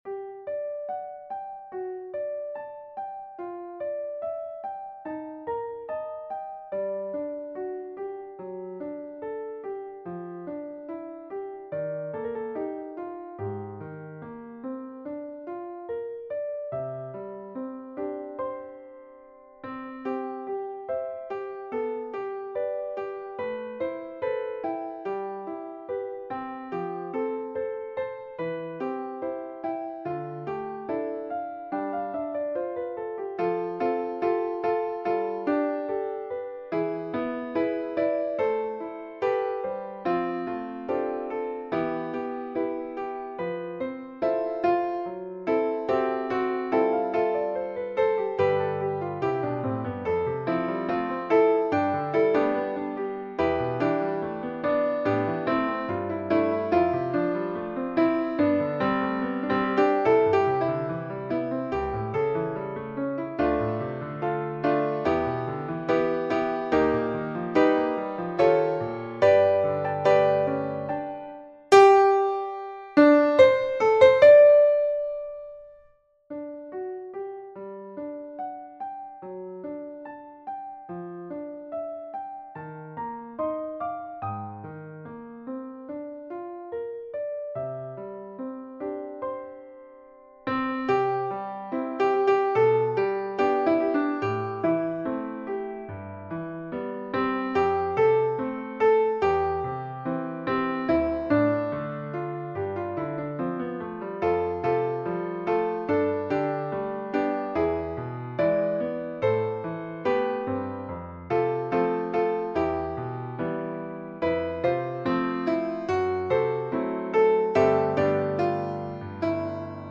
3-part female choir, SSA and piano
世俗音樂
鋼琴漫步在高頻的淨灘，海浪拍打在帕海貝爾的和聲進行上，流暢的級進下行是旅行者好奇而雀躍的步伐。
風一樣的分解和弦，是漂泊的遊子，就連和聲填充都浮動著。
深遠的氣流、綿長的樂句，都是物換星移後的我們，如孩子般地，依偎著曾經懷抱著的人、事、時、地、物。